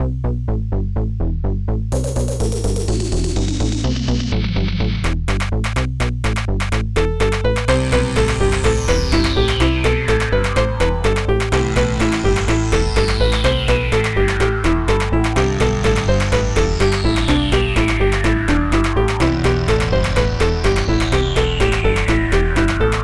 Protracker Module  |  1994-09-19  |  91KB  |  2 channels  |  44,100 sample rate  |  23 seconds
Protracker and family
DRUM05
PIANO
SYNTH01